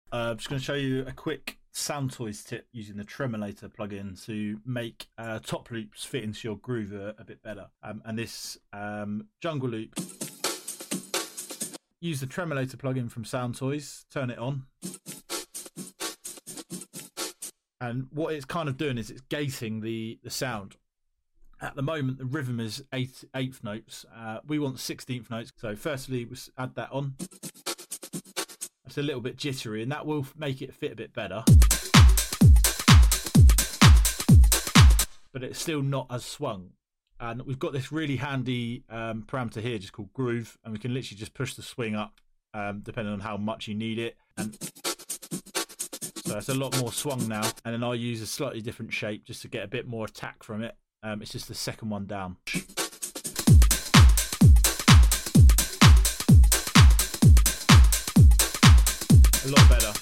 🎶🔊 Check out this quick tip on using the Tremolator by Soundtoys to improve your top loops! 🎛💥 Learn how to add swing and grit to make your grooves feel thicker and layered!